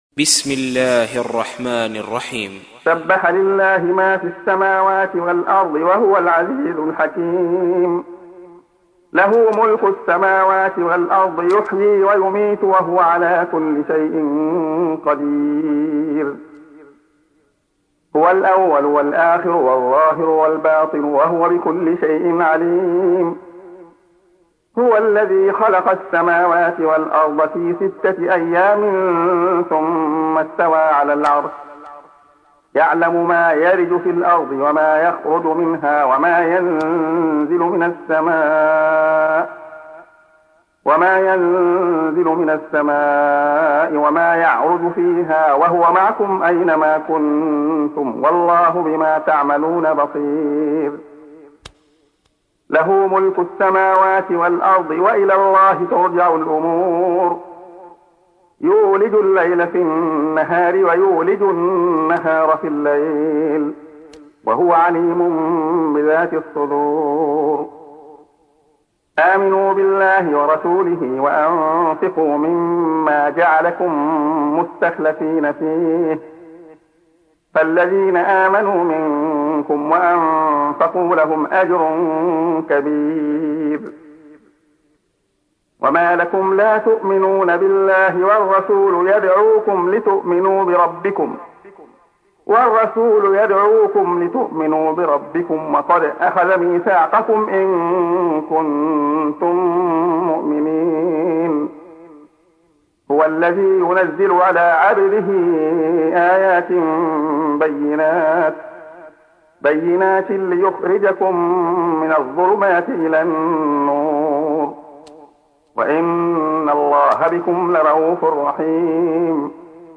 تحميل : 57. سورة الحديد / القارئ عبد الله خياط / القرآن الكريم / موقع يا حسين